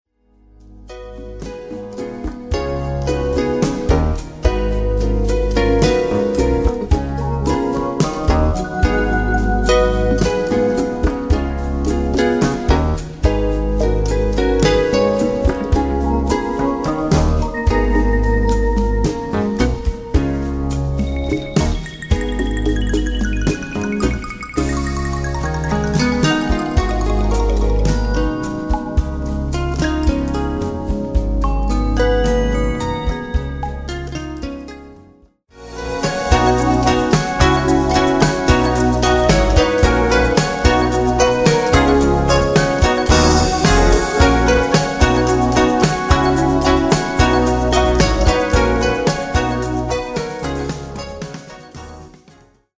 Live recordings from: